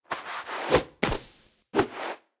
Media:Sfx_Anim_Ultra_Greg.wavMedia:Sfx_Anim_Ultimate_Greg.wav 动作音效 anim 在广场点击初级、经典、高手、顶尖和终极形态或者查看其技能时触发动作的音效
Sfx_Anim_Ultra_Greg.wav